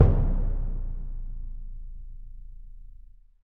Index of /90_sSampleCDs/Roland LCDP03 Orchestral Perc/PRC_Orch Bs Drum/PRC_Orch BD Roll